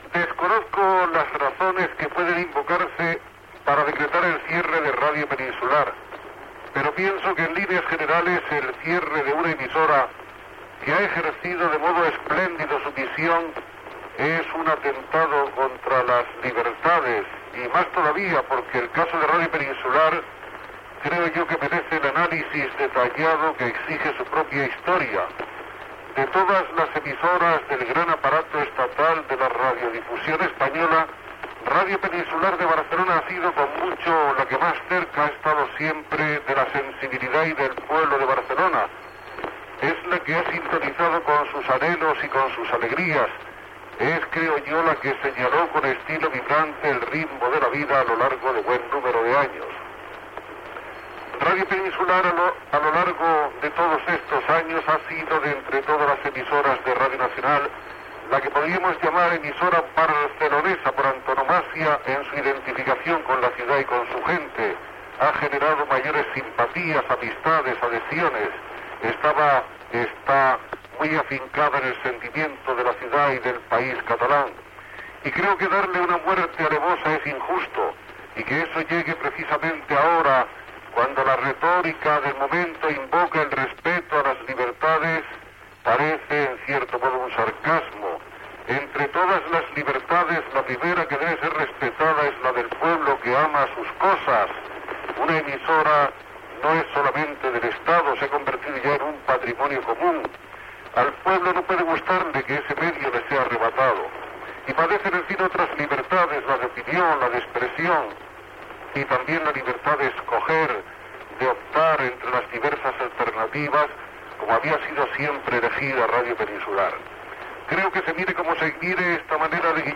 Tancament de l'emissora. Paraules de Joaquín Soler Serrano.